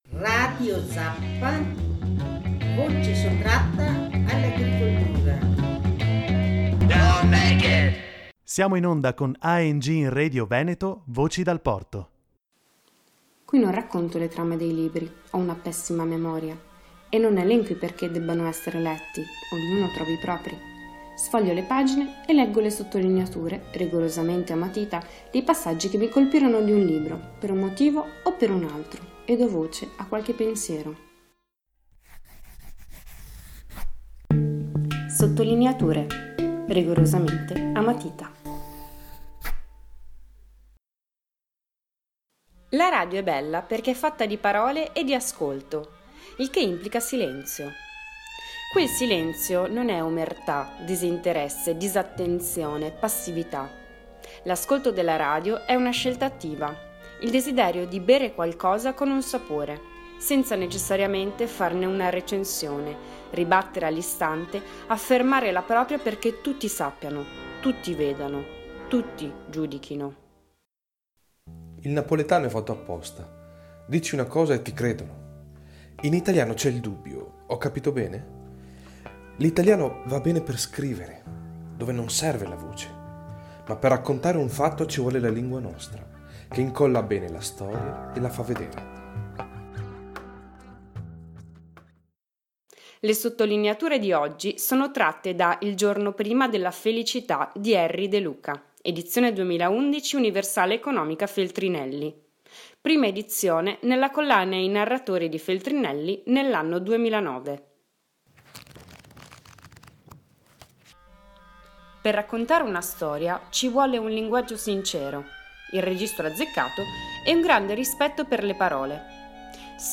Sottolineature rigorosamente a matita per commenti ad alta voce.